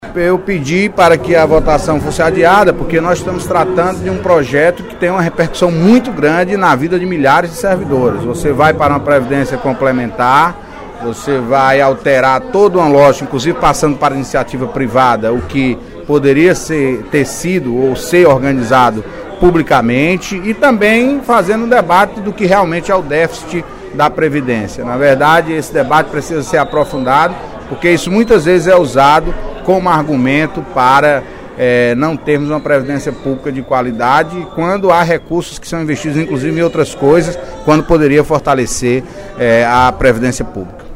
O deputado Antonio Carlos (PT) pediu, no primeiro expediente da sessão plenária desta quinta-feira (29/08), a retirada, da pauta de votação, do projeto de lei que institui o regime de previdência complementar do Estado do Ceará.